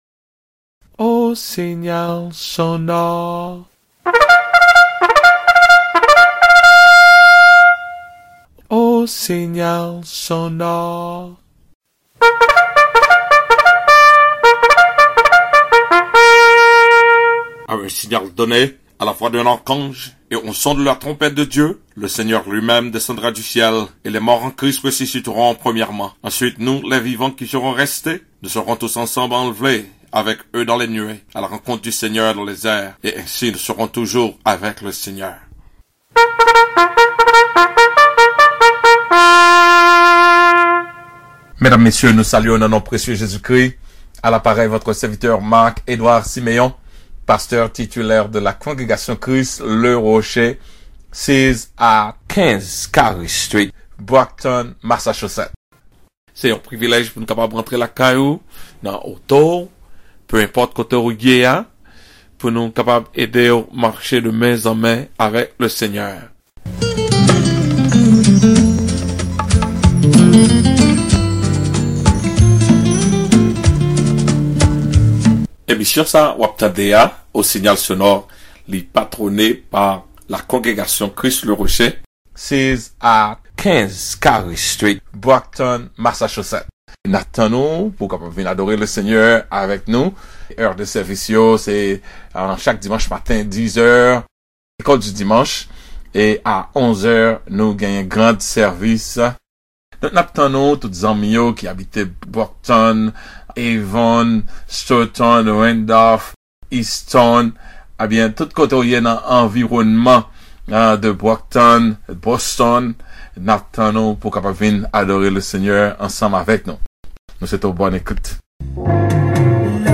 PLEASE CLICK ON THIS LINK TO DOWNLOAD THE MESSAGE: A JOB ON JOB